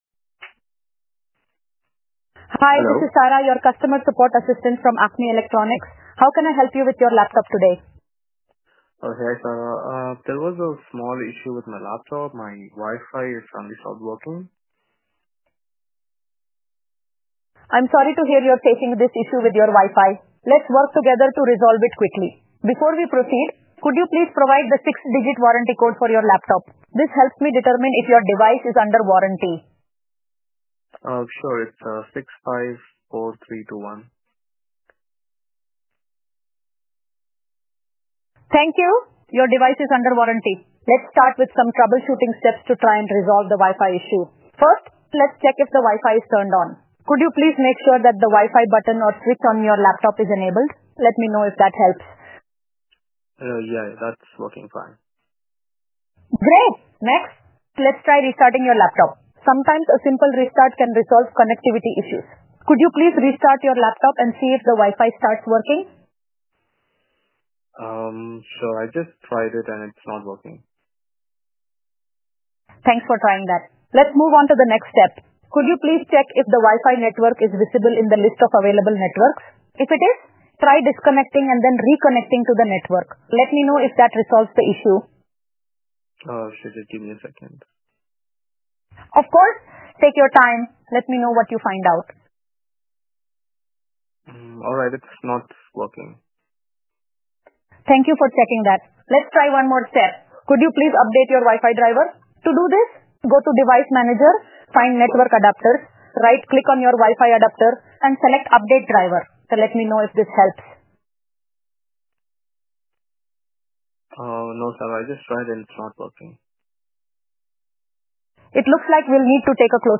See our AI in action